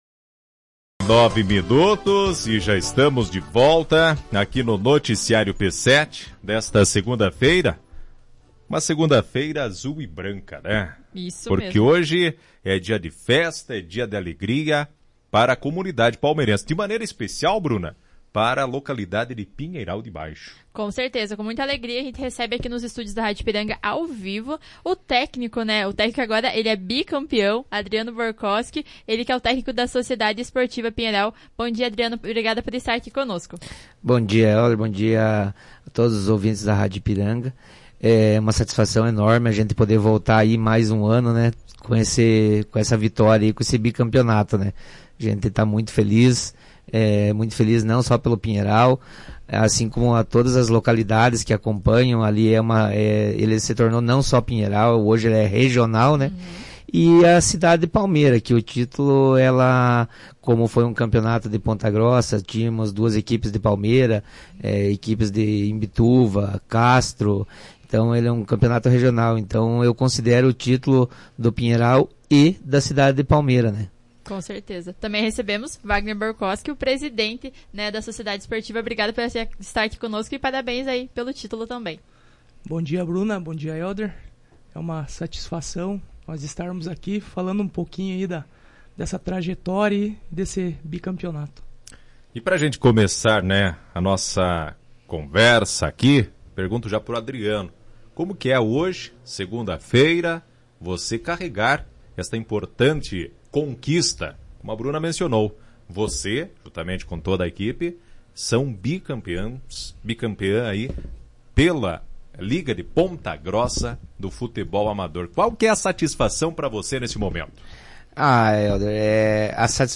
entrevista-pinheiral-05-02-2.mp3